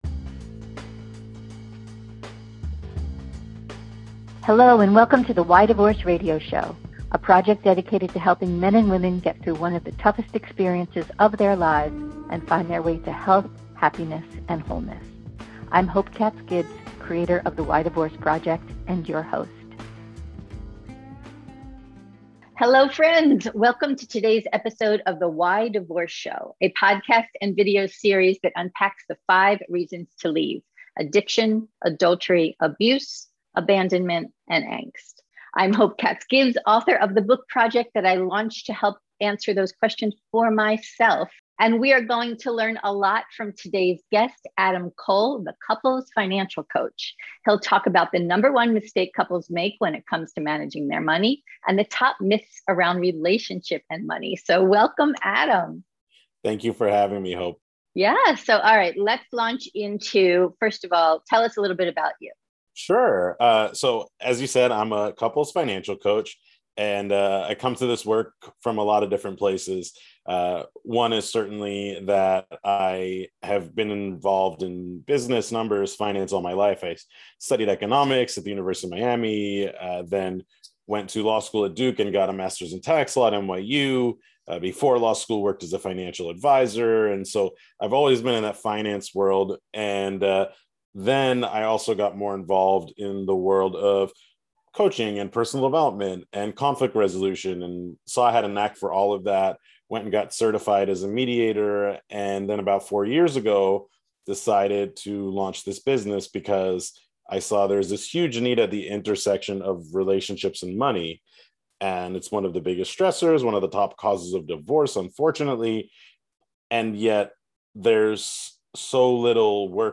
Our interview questions include What is the #1 mistake couples make when it comes to love and money? What are some top myths around this issue? Is there a better choice: joint accounts or separate accounts?